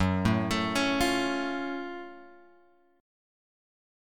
F#mM7 chord {2 0 3 2 2 x} chord